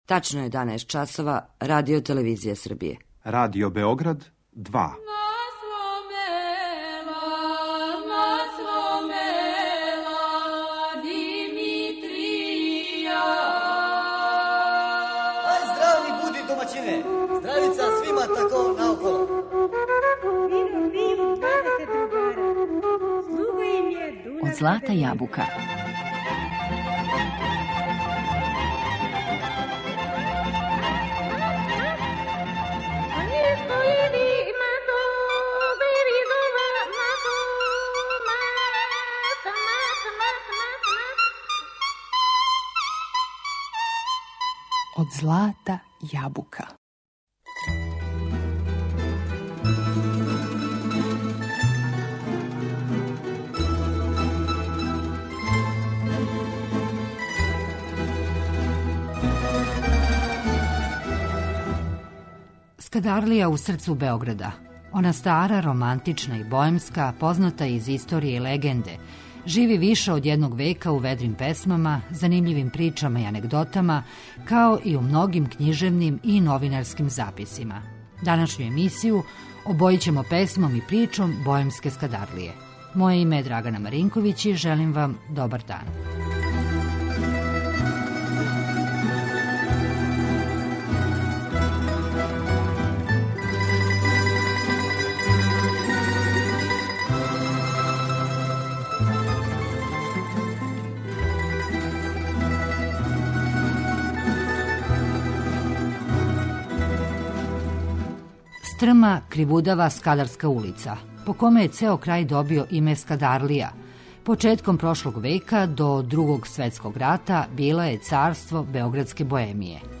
Данашњу емисију обојићемо песмом и причом боемске Скадарлије.